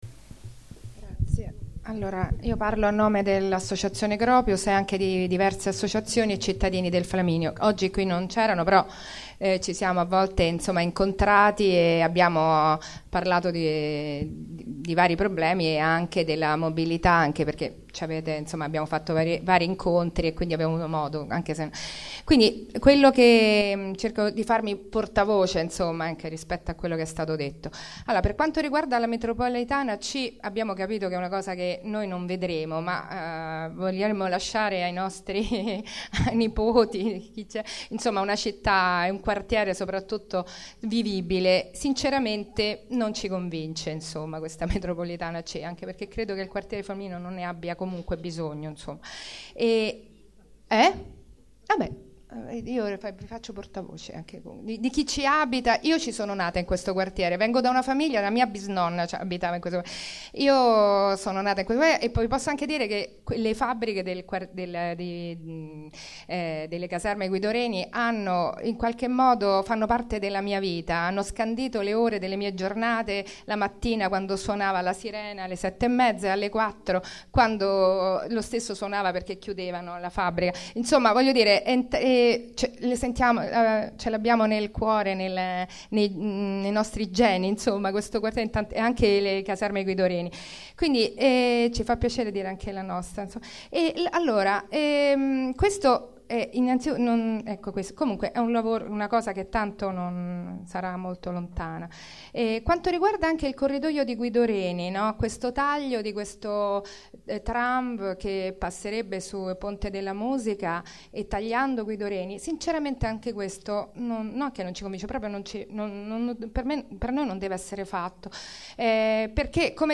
Registrazione integrale dell'incontro svoltosi il 17 aprile 2014 nell'Aula F1 della Facoltà di Architettura in Via Flaminia, 70